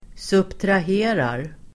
Ladda ner uttalet
Uttal: [subtrah'e:rar]